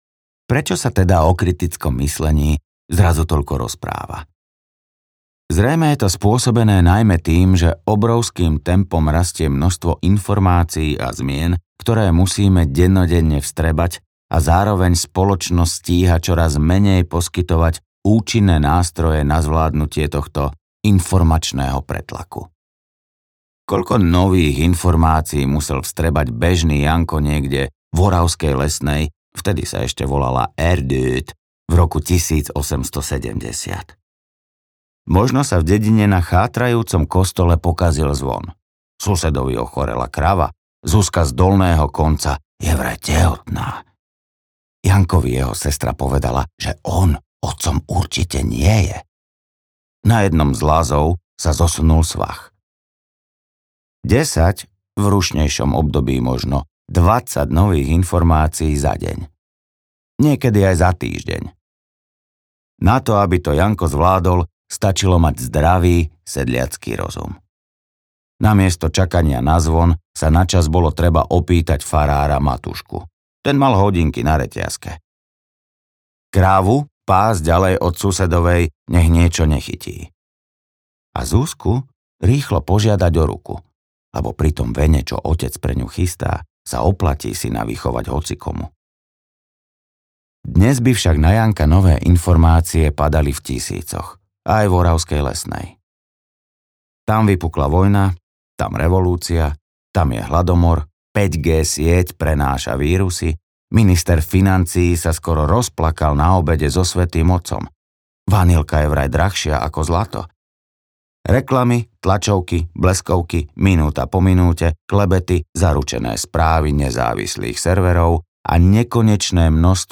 Poriadok v hlave audiokniha
Ukázka z knihy